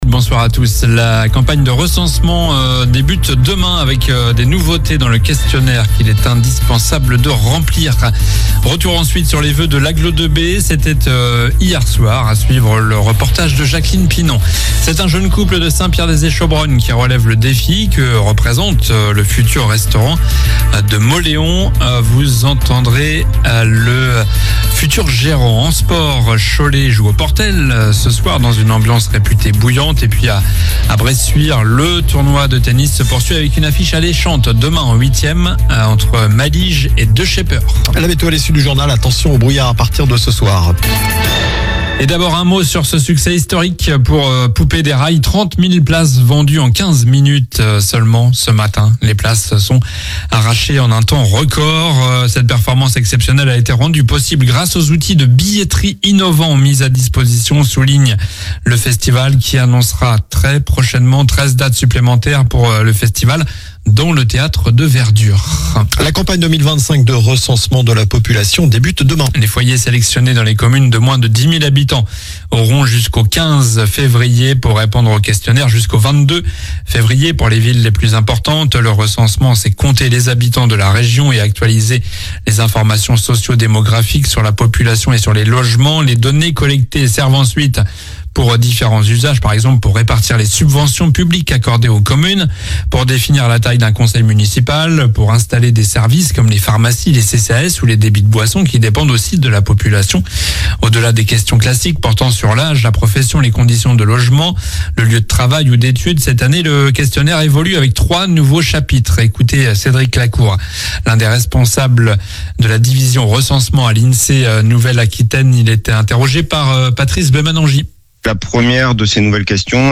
Journal du mercredi 15 janvier (soir)